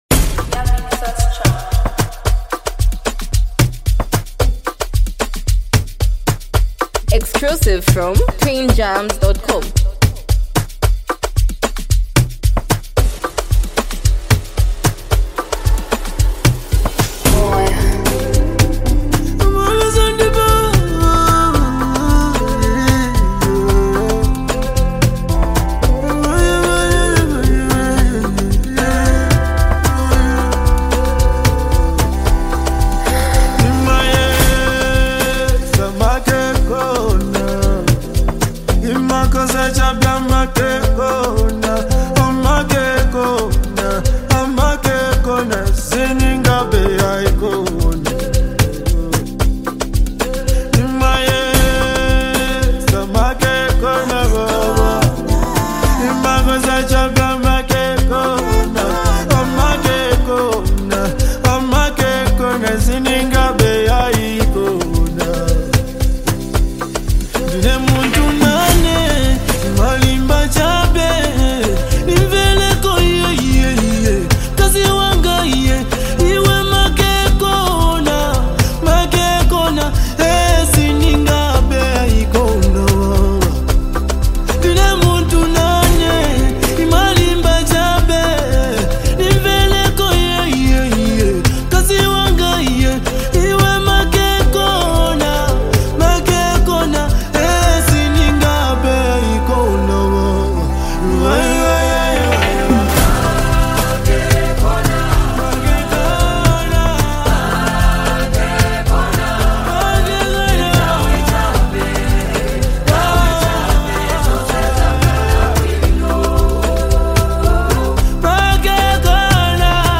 Highly multi talented act and super creative singer